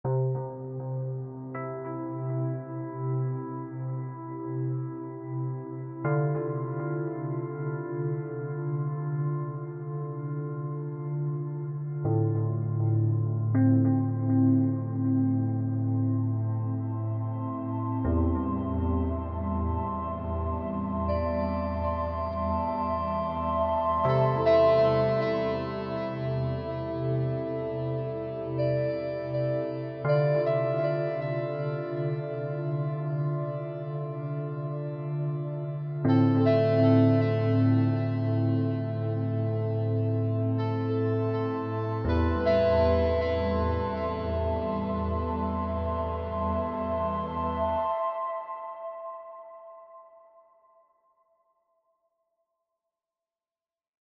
Guitar Harmonics’ consists of a set of warm and realistically recorded harmonics notes of an electric guitar.